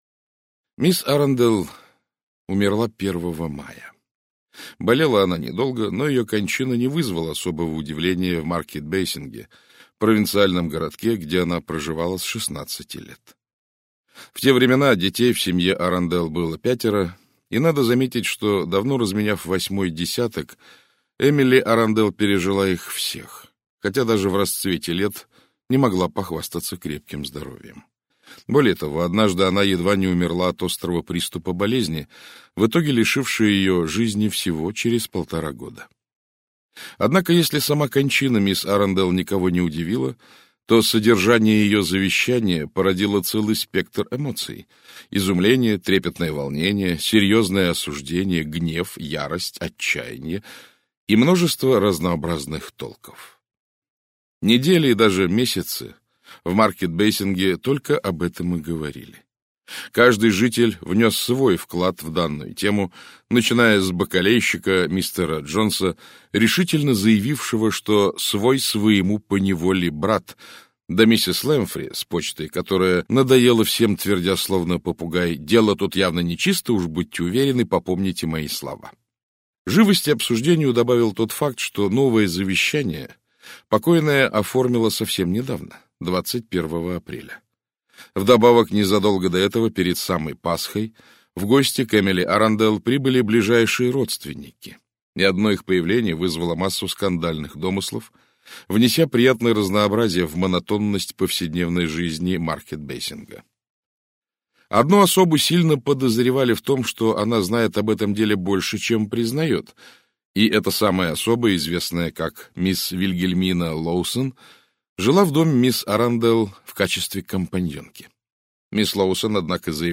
Аудиокнига Немой свидетель - купить, скачать и слушать онлайн | КнигоПоиск